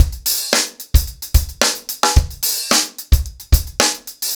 TupidCow-110BPM.55.wav